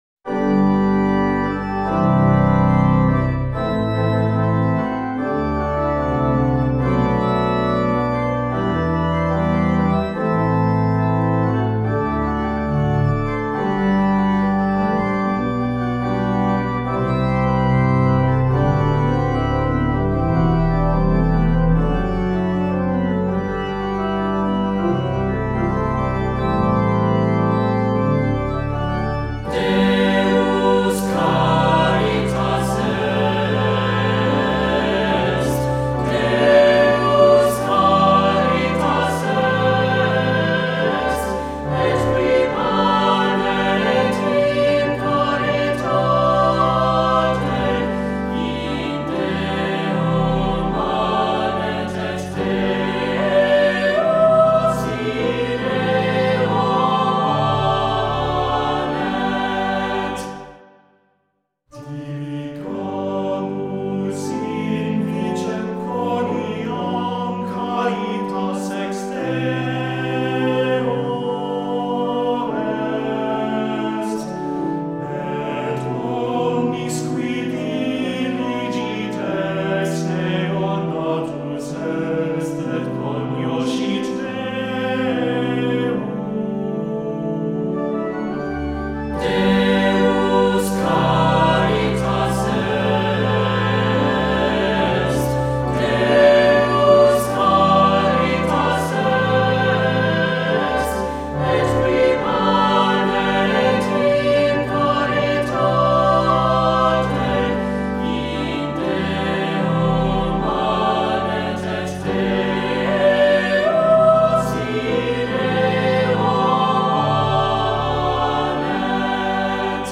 Voicing: Soloist or Soloists,SATB